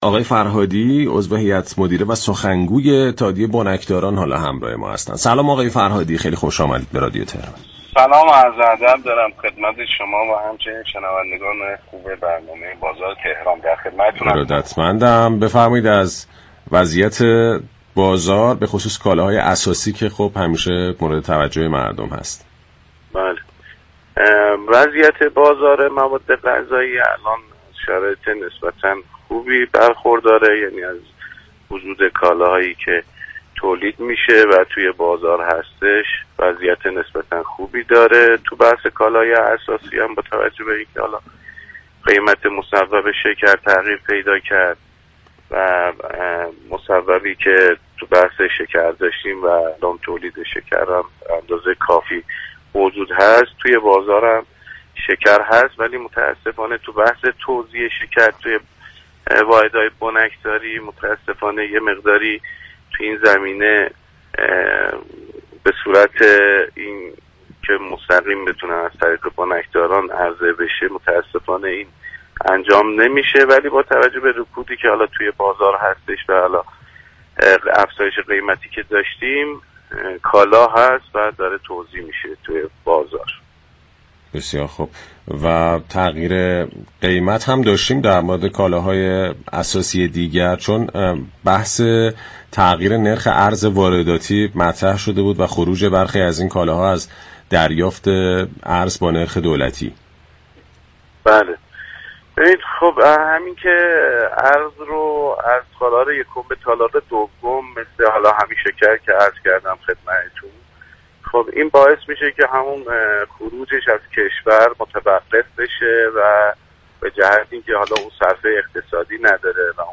در گفت و گو با «بازار تهران» اظهار داشت